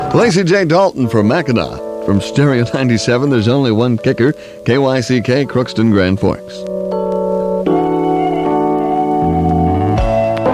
I used a Pioneer AM/FM/cassette stereo unit with built-in telescoping antenna. Source tape is a TDK D90.